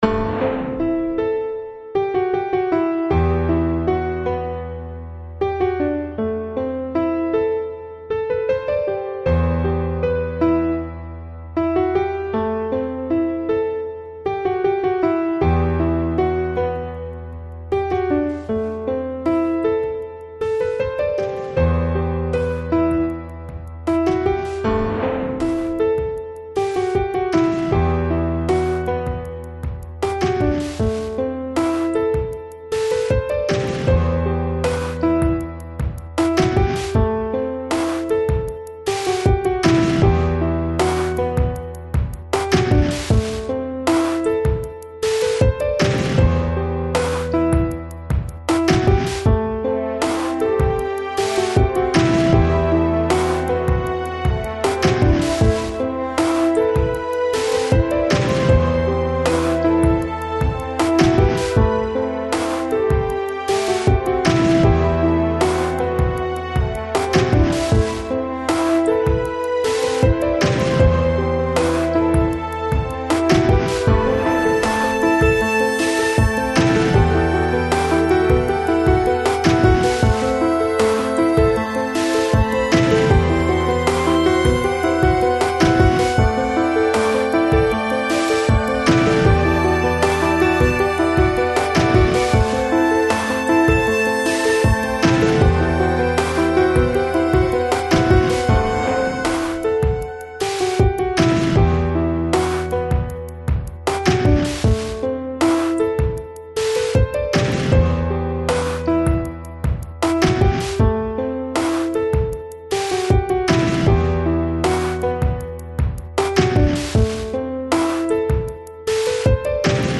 Жанр: Lounge, Chill Out, Lo Fi